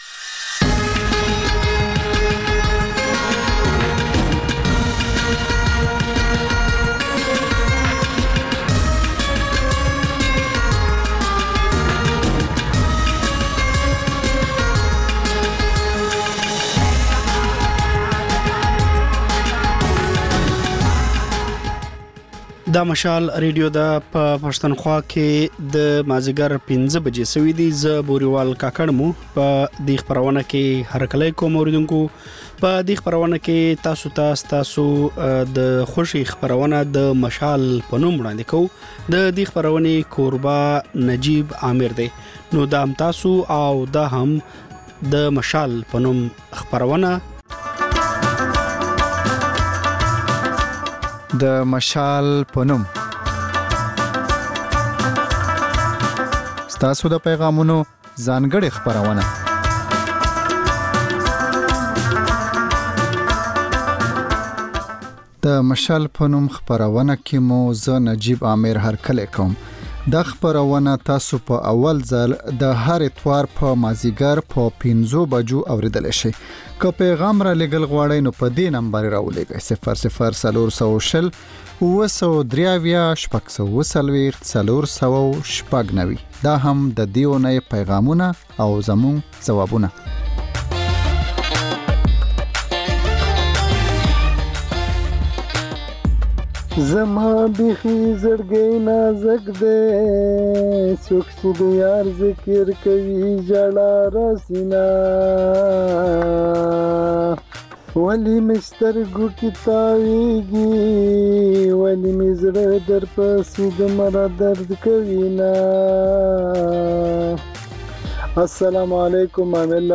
د خپرونې پیل له خبرونو کېږي، بیا ورپسې رپورټونه خپرېږي.